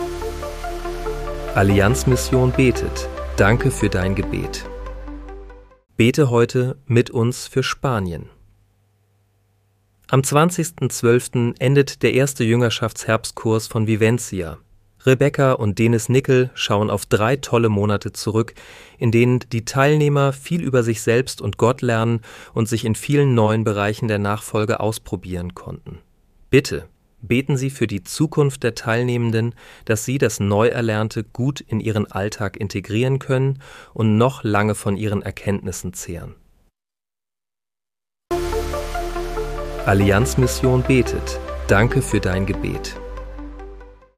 Bete am 20. Dezember 2025 mit uns für Spanien. (KI-generiert mit